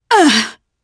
Demia-Vox_Damage_jp_01.wav